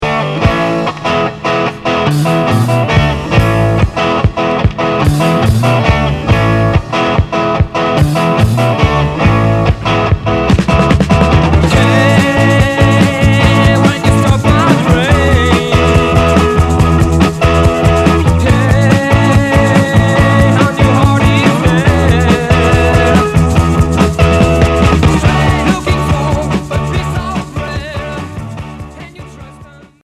Heavy pop